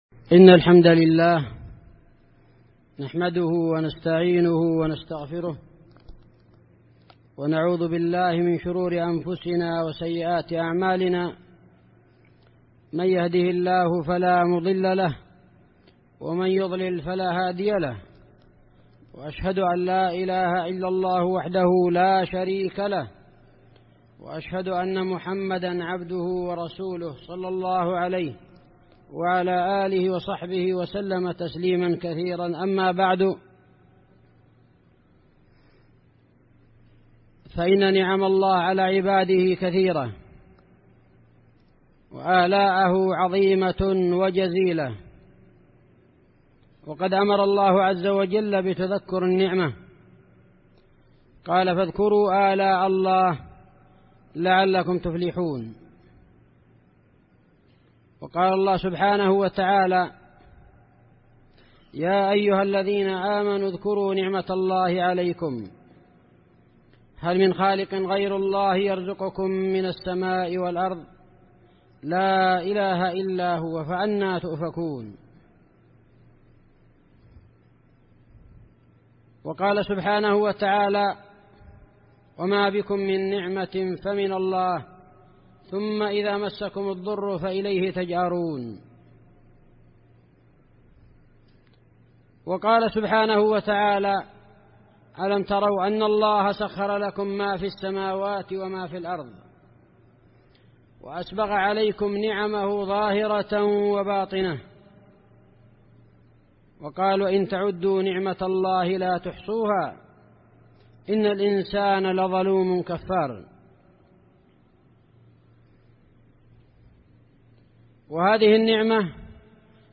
خطبة جمعة بعنوان : (( هدي الأنبياء و الصالحين في تربية البنين ))